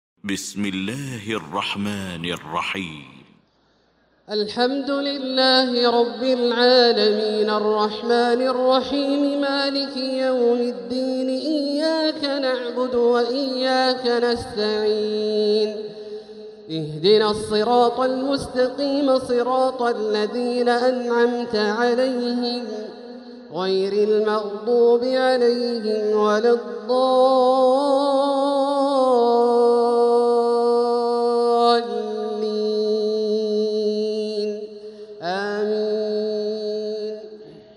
المكان: المسجد الحرام الشيخ: فضيلة الشيخ عبدالله الجهني فضيلة الشيخ عبدالله الجهني الفاتحة